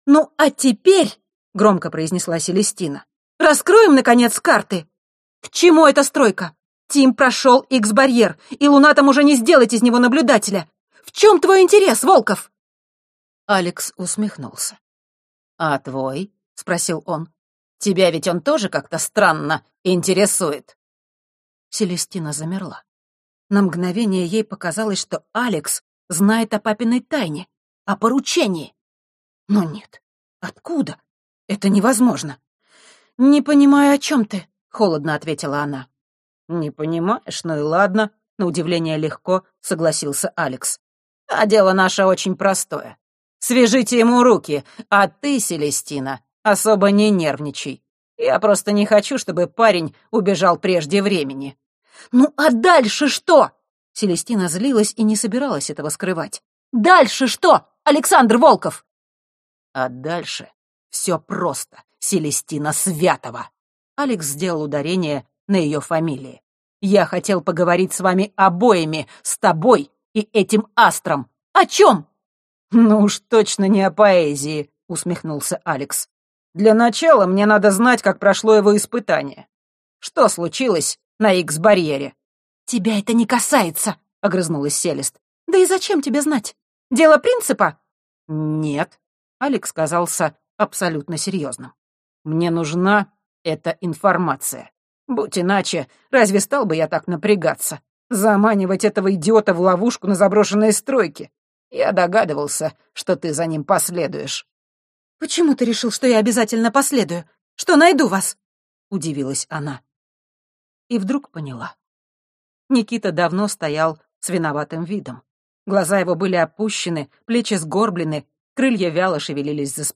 Аудиокнига Лунастры. Прыжок над звездами | Библиотека аудиокниг